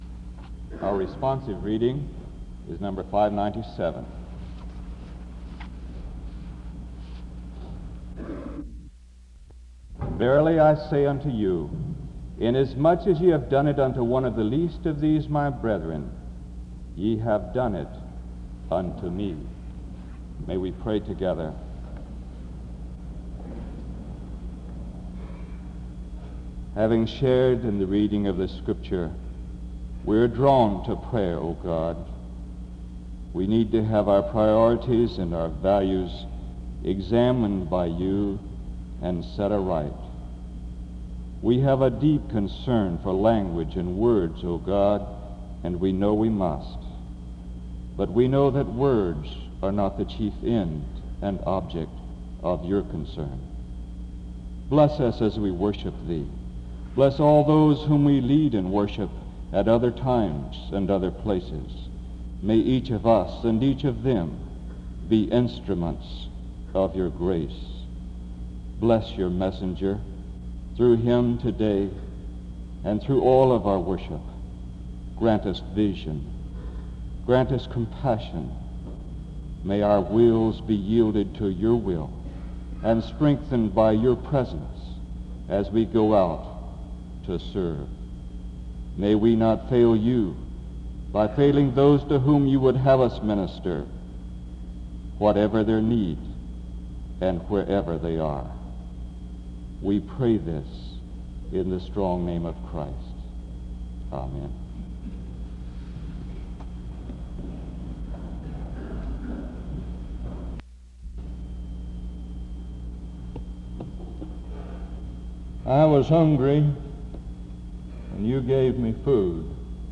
The service begins with a word of prayer from 0:00-1:44.